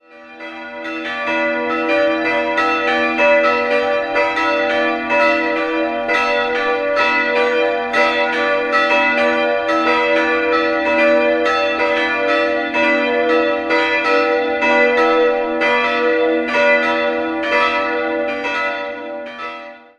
Der Hochaltar entstand um 1800, die beiden Seitenaltäre stammen aus einer säkularisierten Kirche in Eichstätt und wurden 1811 in Arnsberg eingebaut. 3-stimmiges TeDeum-Geläute: h'-d''-e'' Die Glocken wurden 1955 von Friedrich Wilhelm Schilling in Heidelberg gegossen.